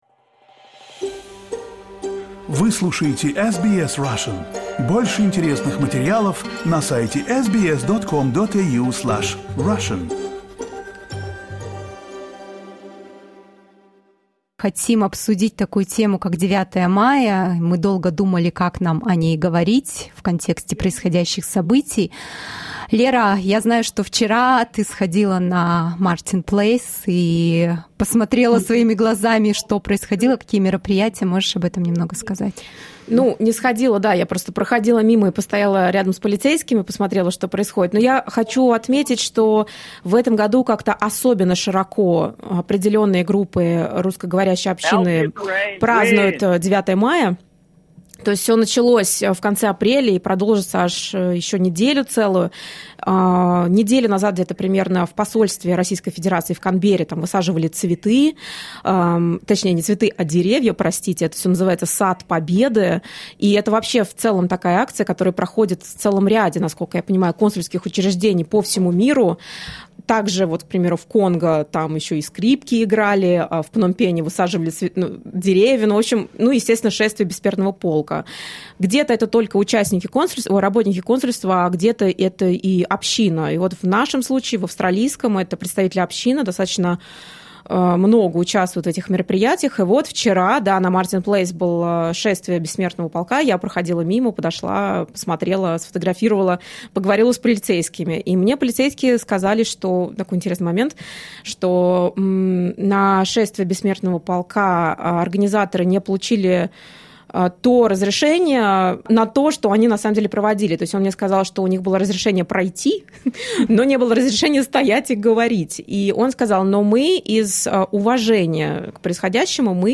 Listen to a segment from today's live show, where we talked about the events that the Russian-speaking community is holding in Sydney on May 9, and also interviewed an activist of the Russian community, who at that moment was at the protest of the Ukrainian community in Central Sydney.